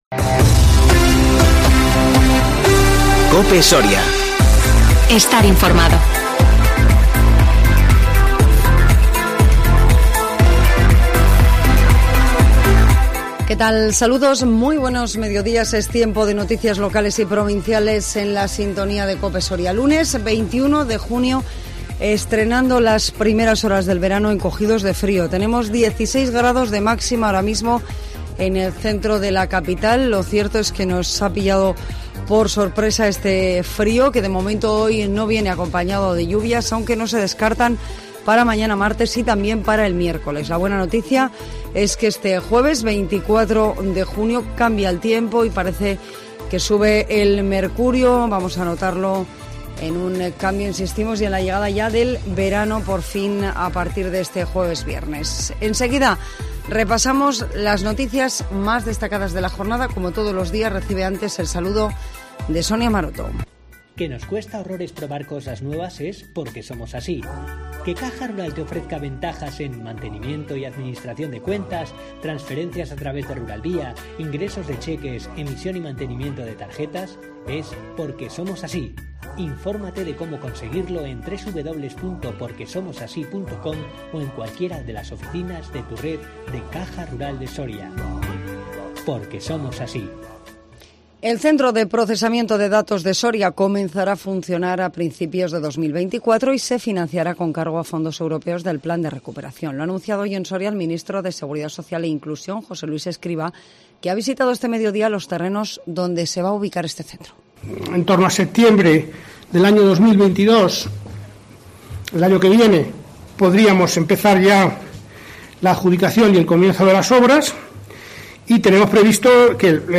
INFORMATIVO MEDIODÍA 21 JUNIO 2021